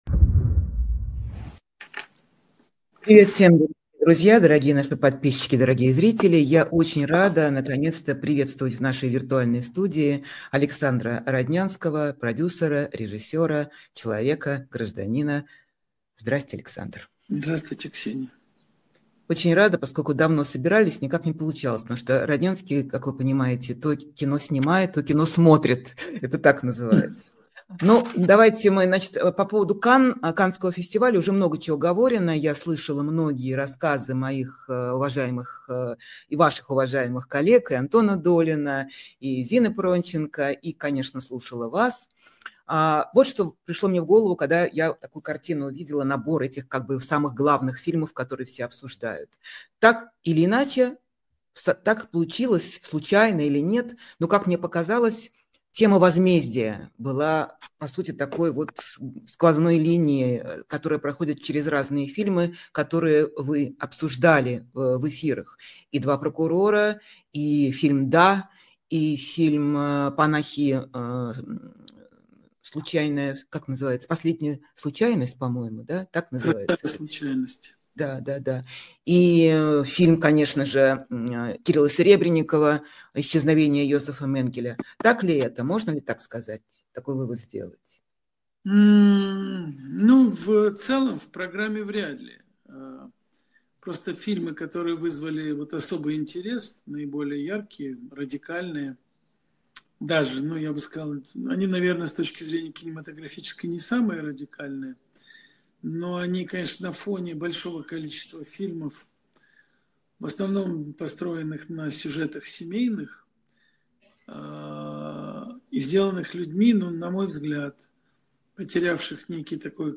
Эфир ведёт Ксения Ларина